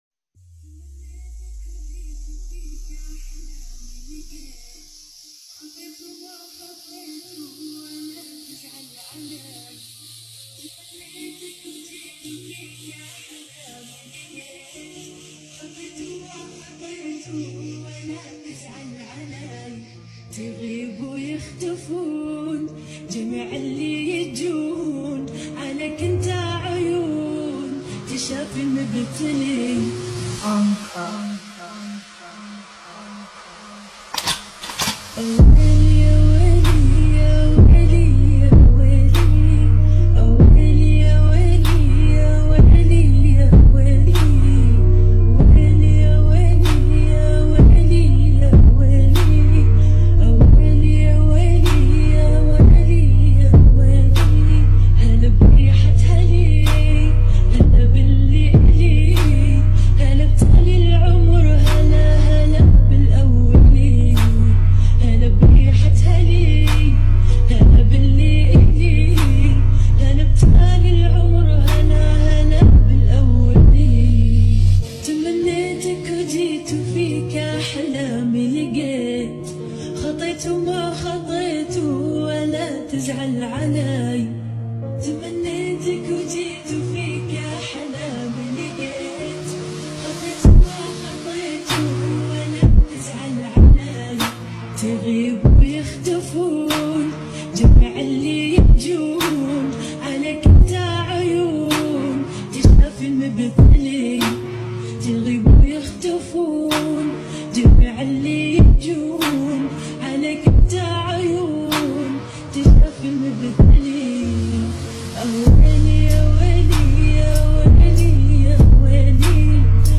remix song